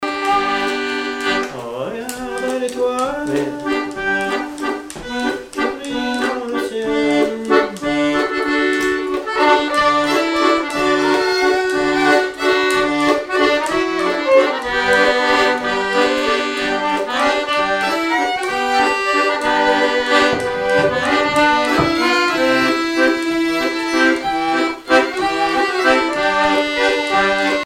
danse : valse
violon
Pièce musicale inédite